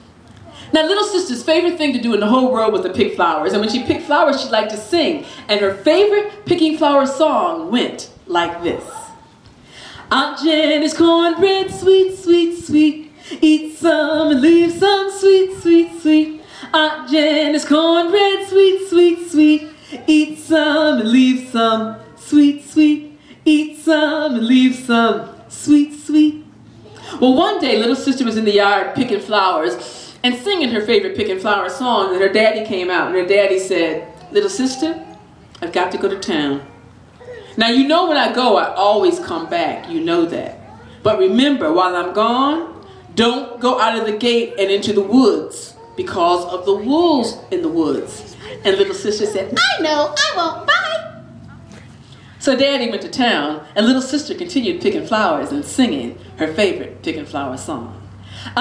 a snippet from BIG story fest 2012